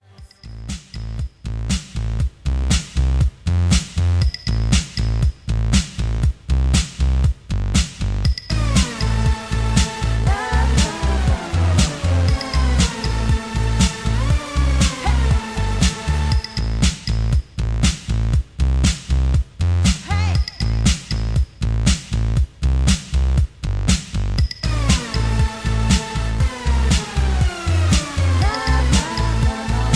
mp3 backing tracks